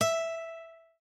lute_e.ogg